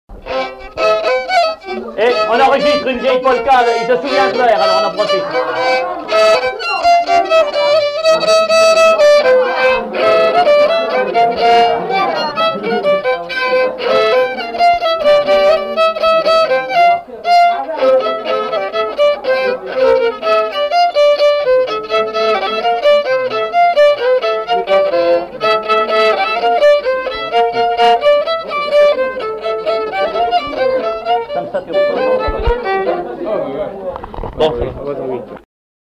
Résumé instrumental
danse : polka
répertoire d'airs de danse au violon
Pièce musicale inédite